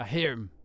Voice Lines / Barklines Combat VA